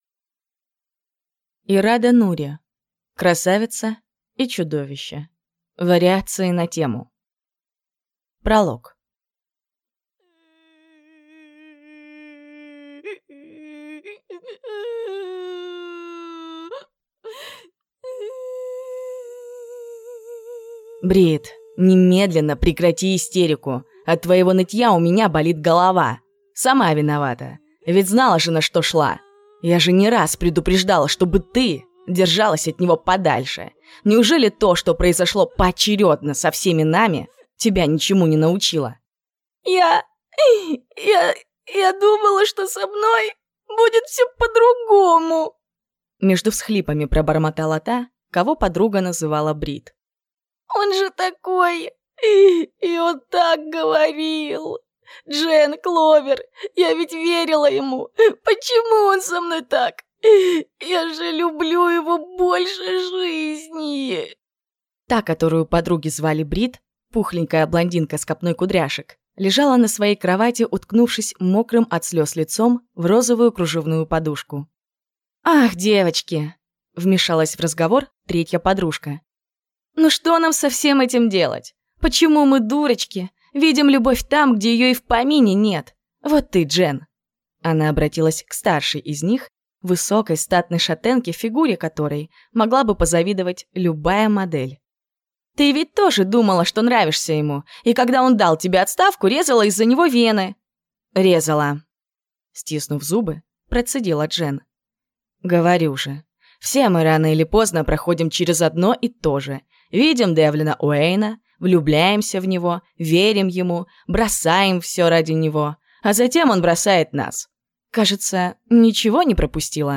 Аудиокнига Красавица и чудовище. Вариации на тему | Библиотека аудиокниг
Прослушать и бесплатно скачать фрагмент аудиокниги